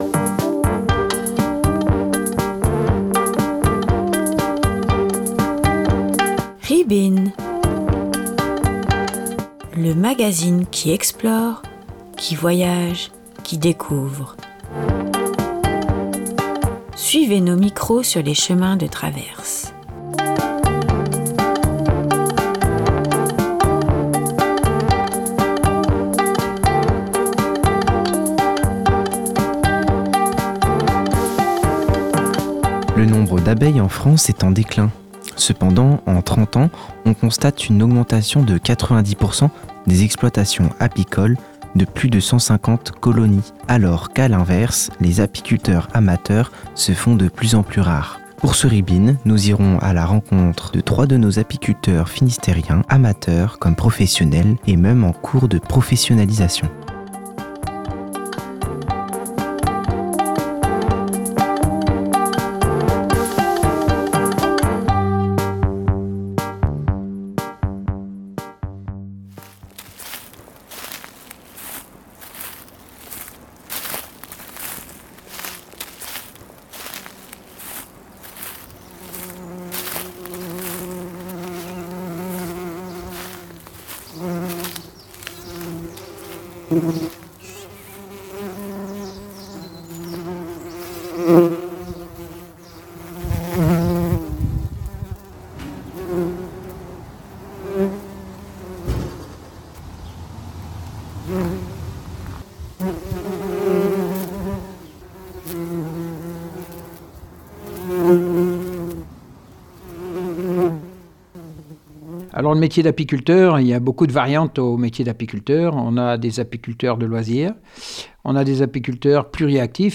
Reportage chez trois apiculteurs du Finistère qui nous racontent leur passion et leurx expériences